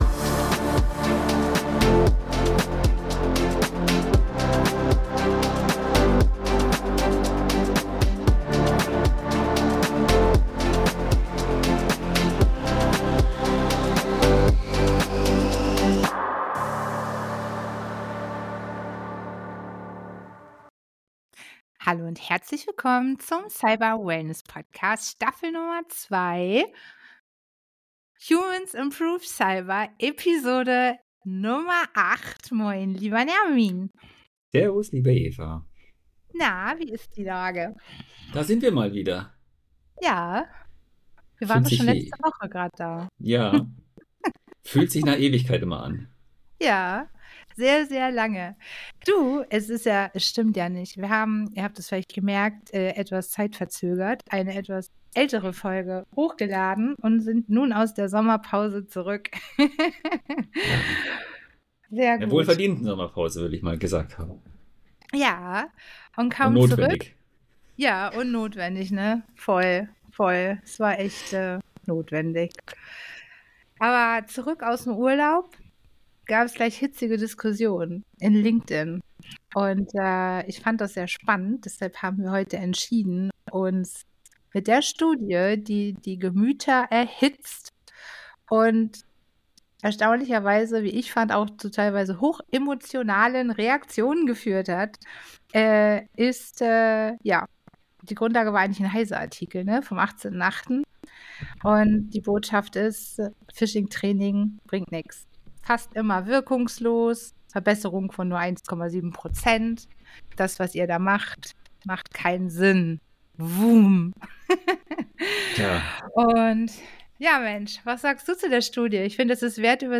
Ein ehrliches Gespräch über Verantwortung, Kultur und die Zukunft von Sicherheit im Zeitalter von Mensch & KI.